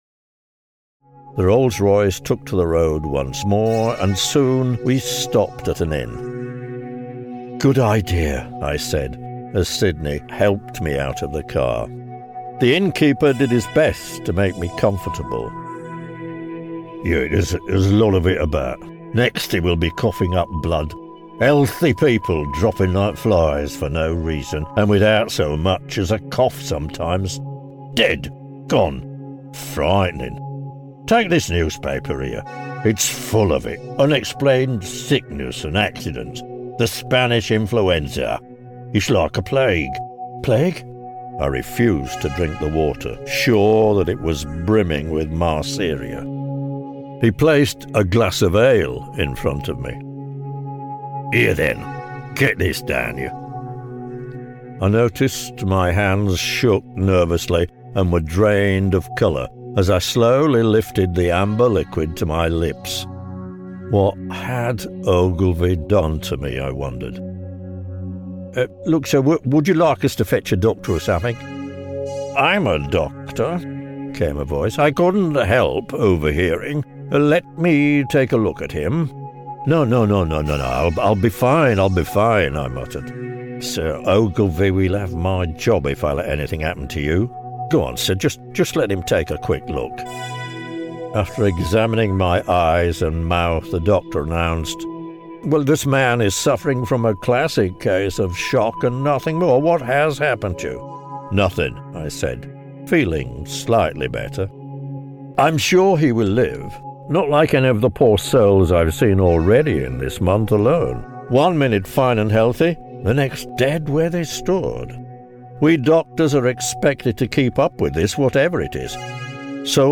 Audiobook: $ 12.95